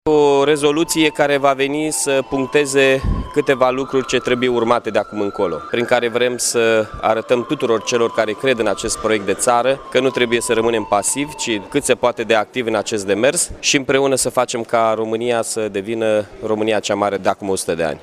Cei peste 100 de aleși locali din România și Republica Moldova, reuniți la Palatul Culturii, au semnat, astăzi, Rezoluția de la Iași prin intermediul căreia se solicită ca unirea Basarabiei cu România să devină proiect de țară.
Primarul Mihai Chirica a subliniat importanța evenimentului de astăzi și a principiilor  stabilite prin Rezoluția semnată: